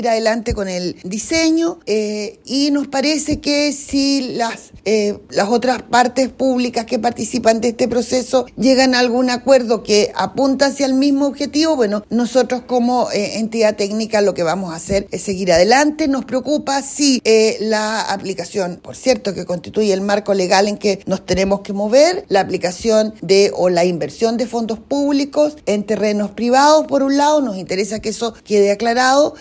Al respecto, la directora del Serviu, María Luz Gajardo, señaló que si las otras partes están trabajando con el mismo objetivo de acelerar la obra, ellos seguirán adelante.